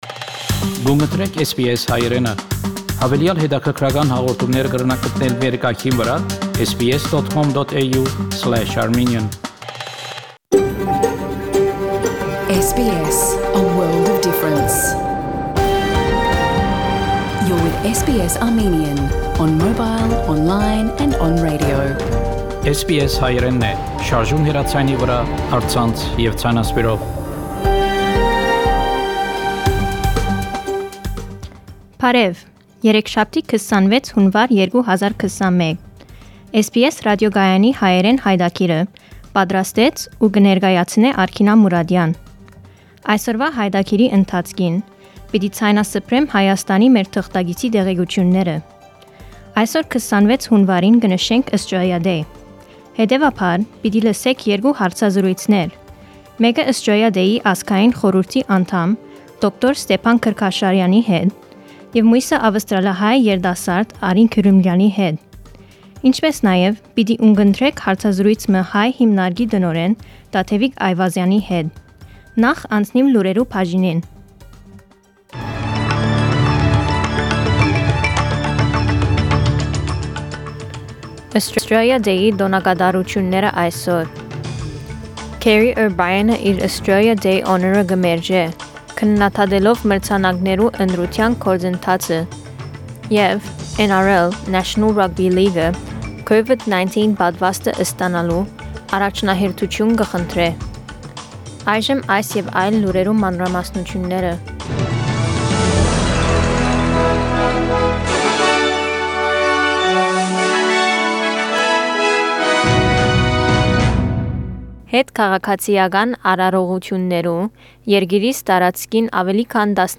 SBS Armenian news bulletin from 26 January 2021 program.